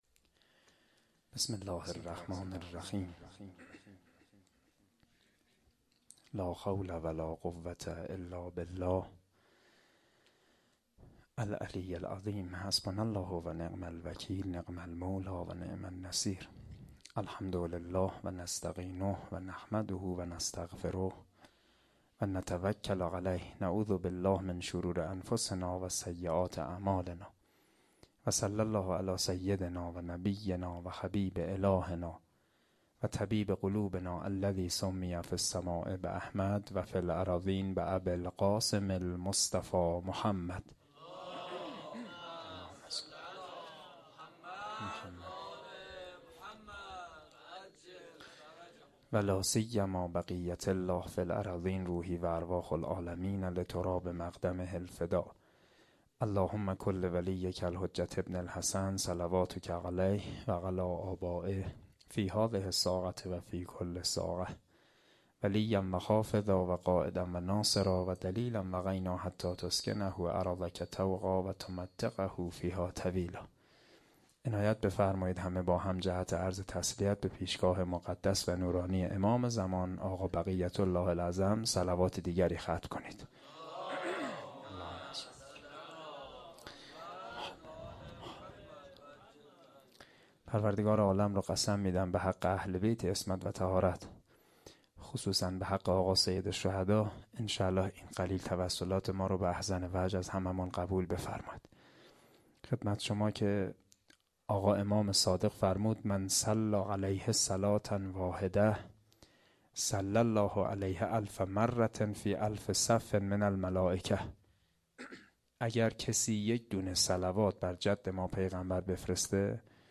asheghan-sokhanrani2.mp3